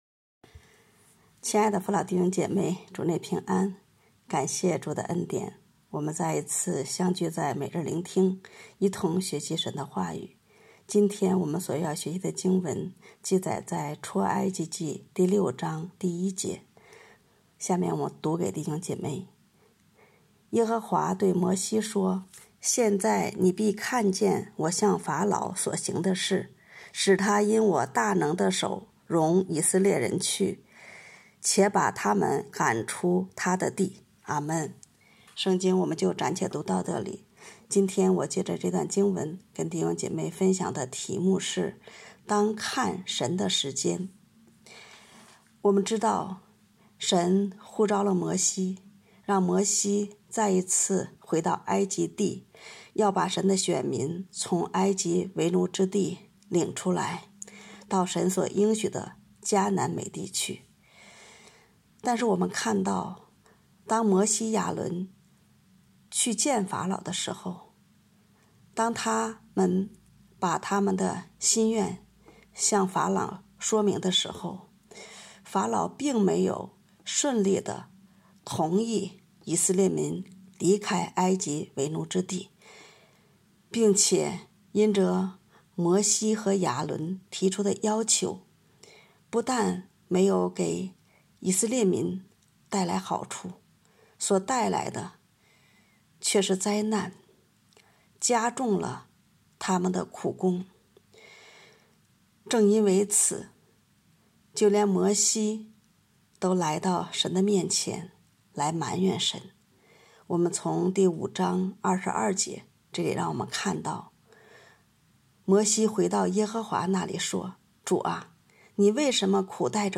当看神的时间》 证道